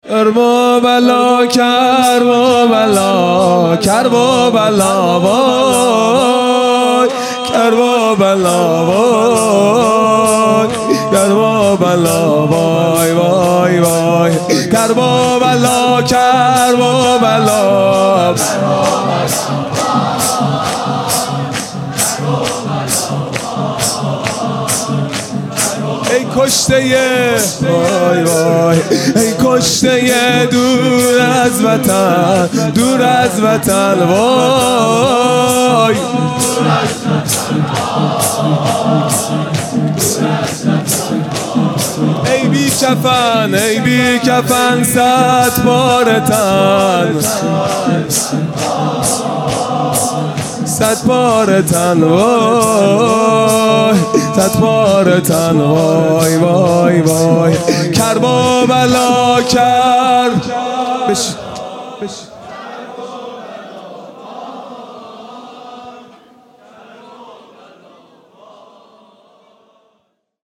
خیمه گاه - هیئت بچه های فاطمه (س) - شور | ای کشتۀ دور از وطن
محرم ۱۴۴۱ |‌ شب پنجم